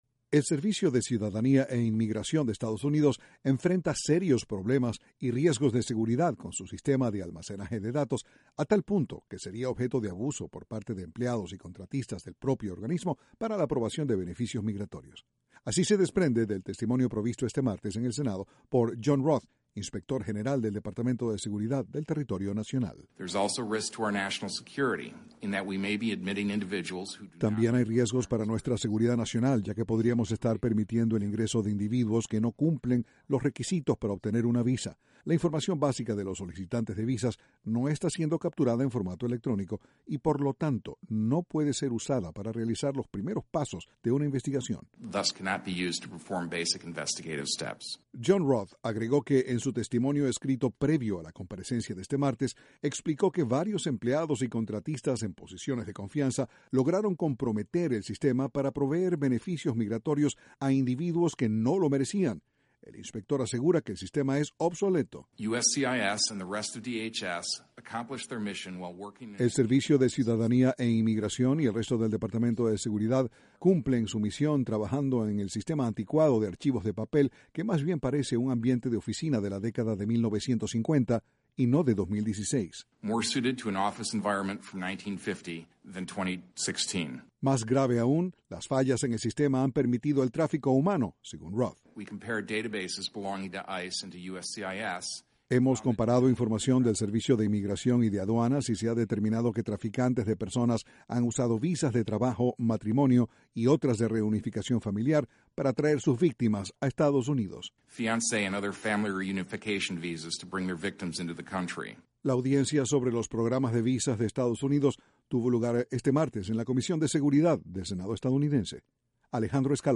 Fraude migratorio y tráfico humano en la emisión de visas para entrar a Estados Unidos fueron algunos de los temas tratados durante una audiencia en el Senado. Desde la Voz de América, Washington